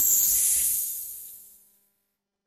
A bright, twinkling enchantment shimmer with crystalline chimes and fading sparkles
enchantment-shimmer.mp3